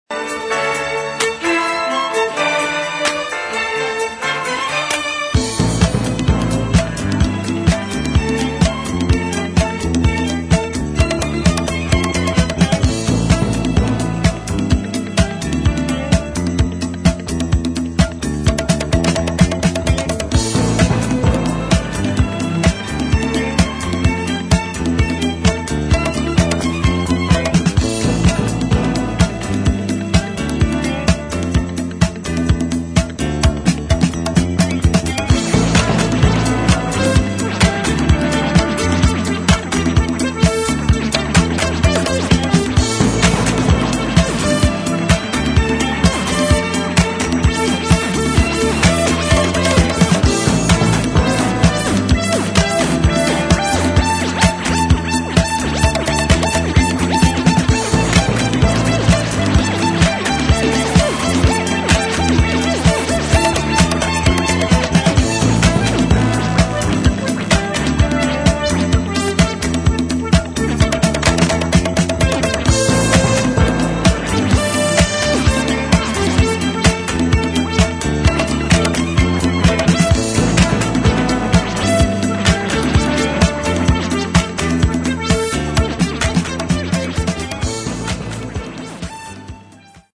[ DEEP HOUSE | DISCO ]